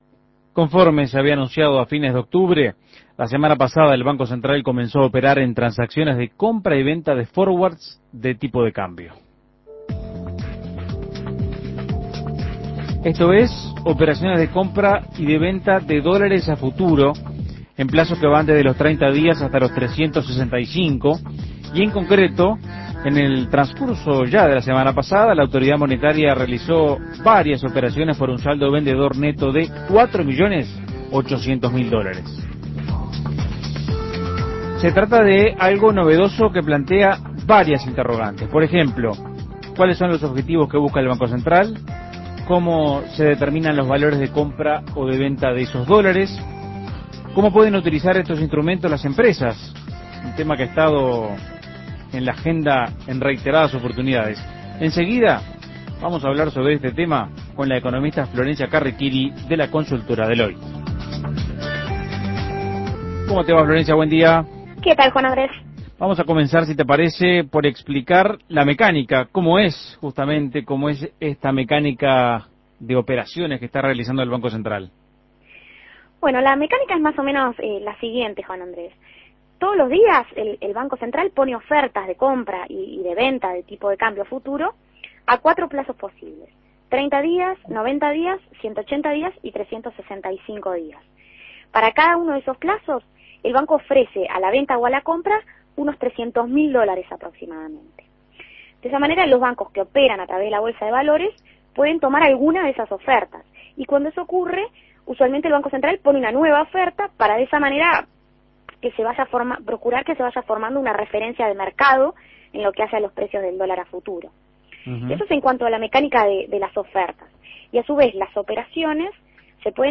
Análisis Económico El Banco Central comenzó a realizar operaciones forward.